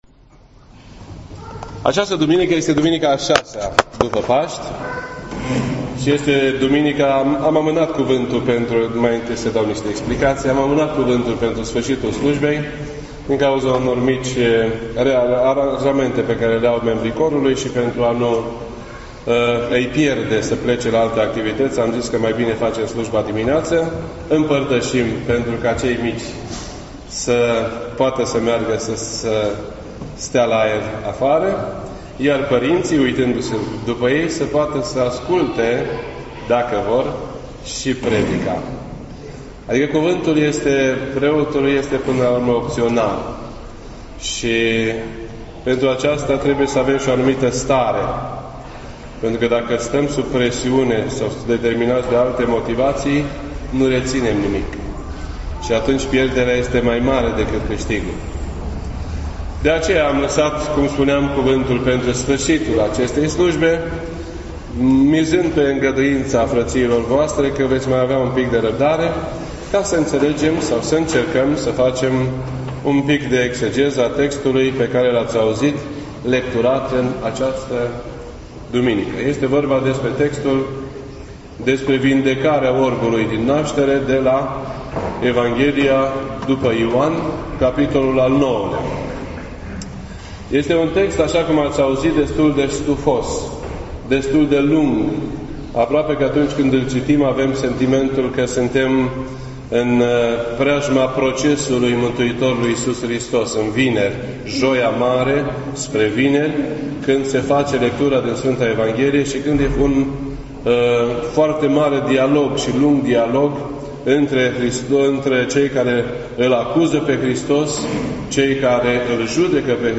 This entry was posted on Sunday, May 21st, 2017 at 7:03 PM and is filed under Predici ortodoxe in format audio.